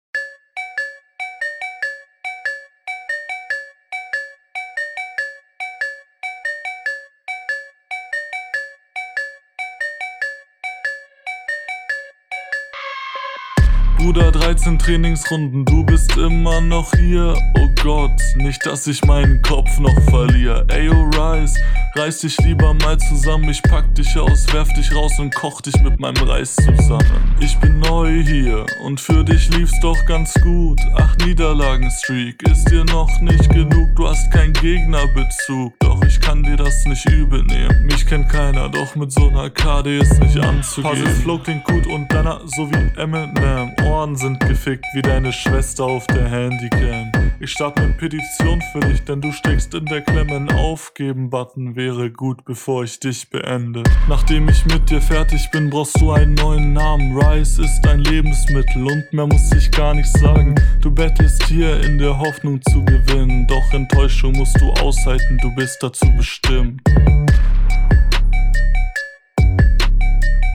Der Beat liegt dir mehr. Stimme leider wie in deiner Runde zuvor auch gelangweilt. Textlich …
beat gefällt mir. text ist auch gut finde du köntest manchmal etwas schneller rappen auf …
Beat ist cool. Feier ich. Flow find ich hier auch schon viel cooler als vorher. …